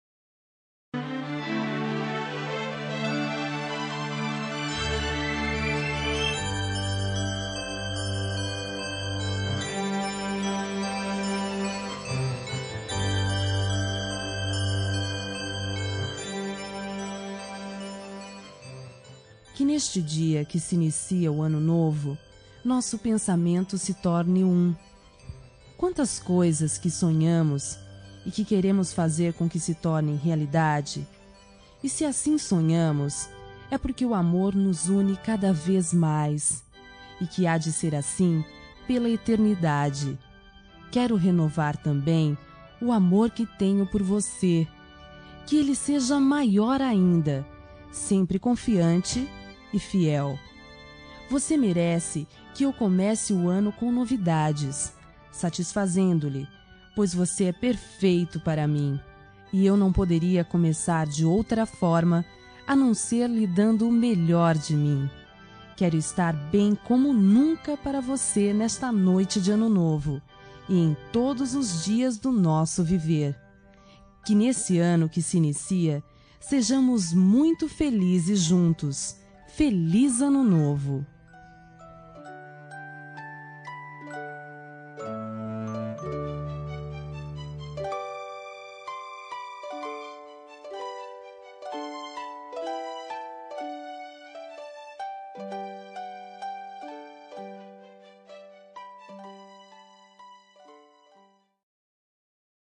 Ano Novo – Romântica – Voz Feminina – Cód: 6420